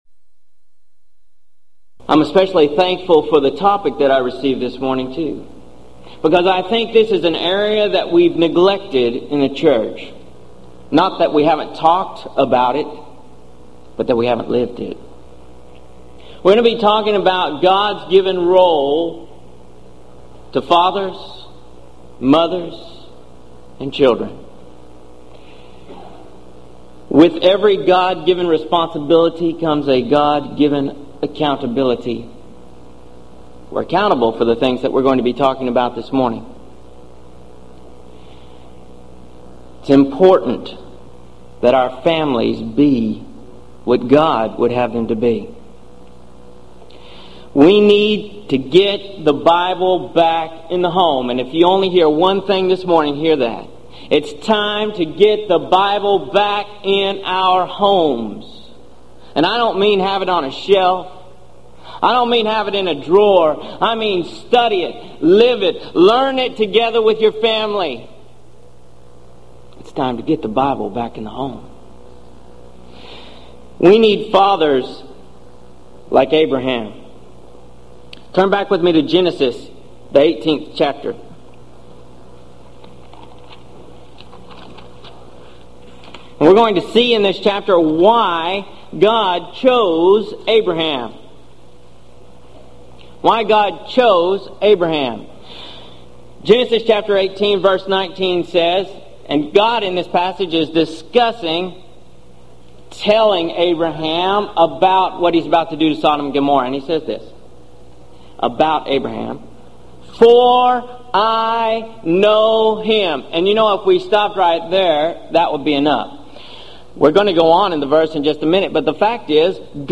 Event: 1996 Gulf Coast Lectures Theme/Title: Lively Issues On The Home And The Church
lecture